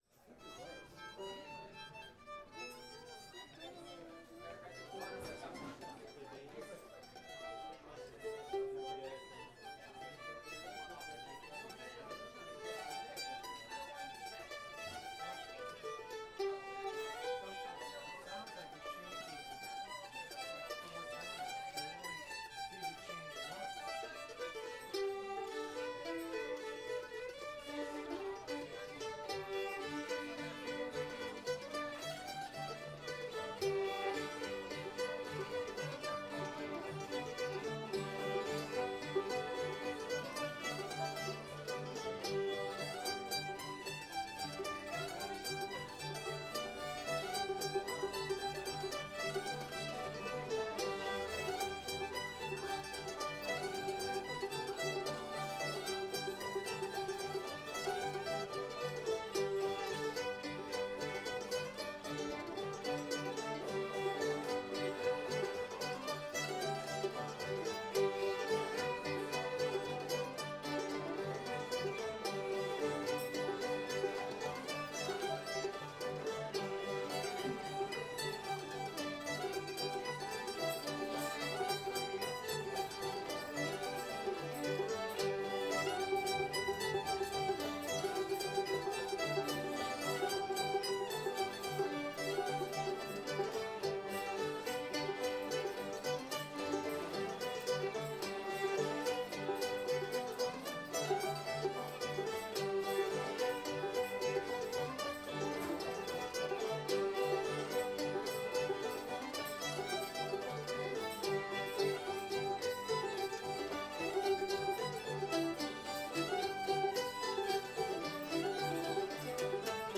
hezekiah [G]